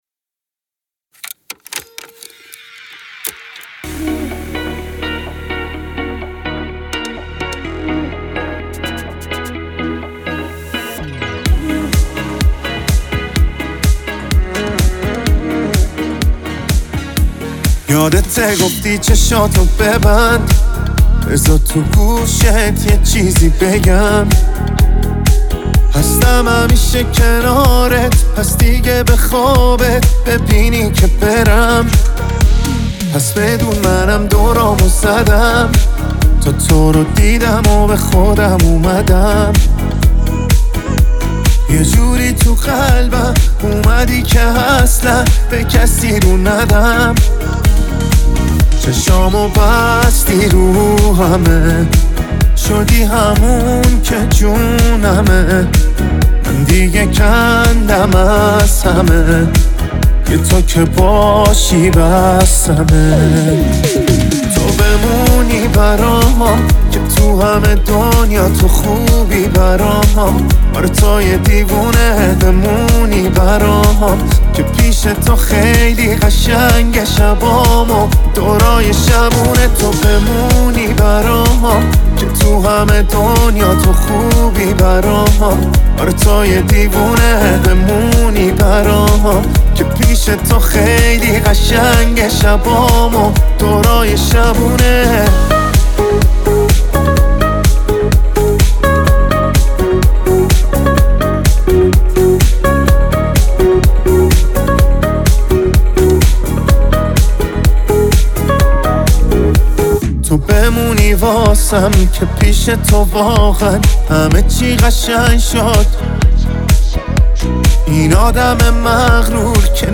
موزیک جذاب وعاشقانه